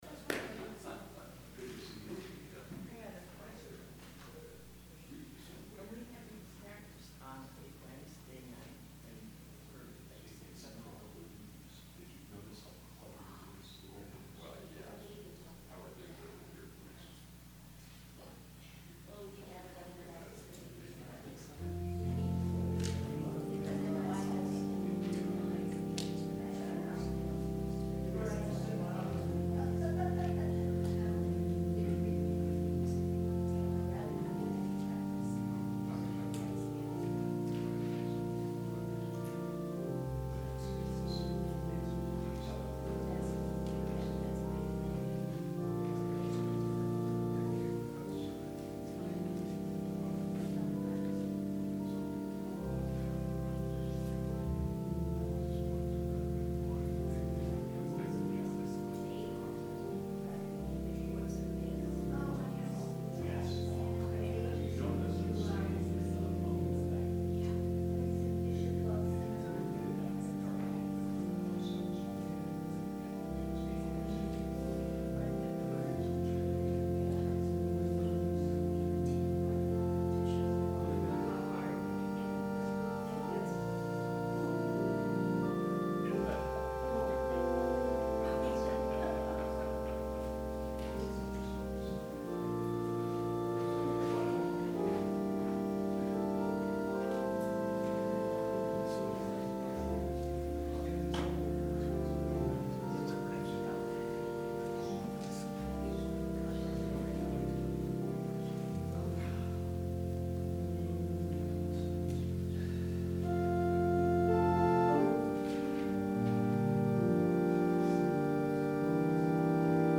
Sermon – October 21, 2018